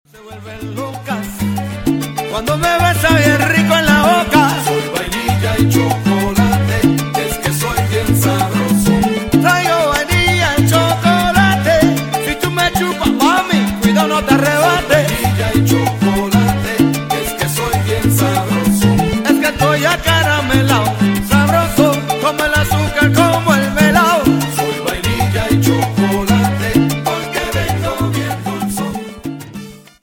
Salsa Charts - November 2008